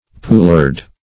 poulard - definition of poulard - synonyms, pronunciation, spelling from Free Dictionary
Search Result for " poulard" : The Collaborative International Dictionary of English v.0.48: Poulard \Pou*lard"\ (p[=oo]*l[aum]rd"), n. [F. poularde pullet, fr. poule hen.